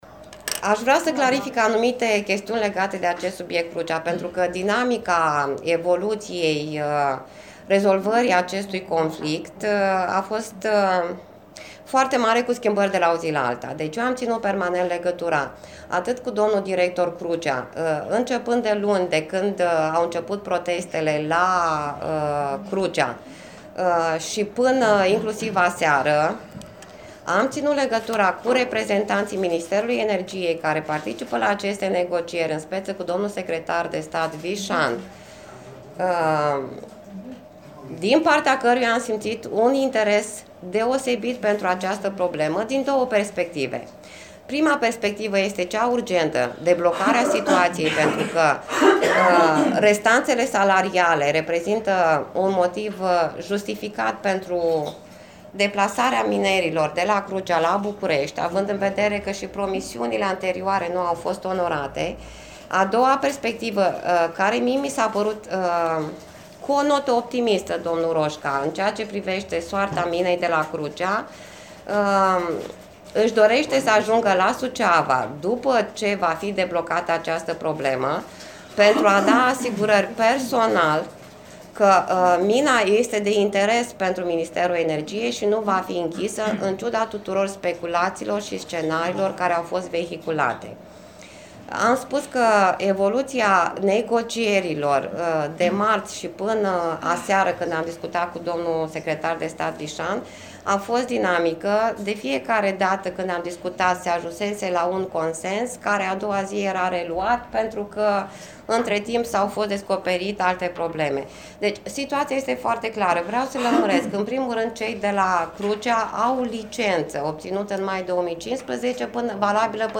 Despre discuţiile avute cu oficiali ai Ministerului Energiei şi despre cum speră că va fi deblocată situaţia a vorbit, astăzi, prefectul judeţului Suceava, Mirela Adomnicăi: